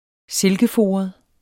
Udtale [ -ˌfoːʌð ]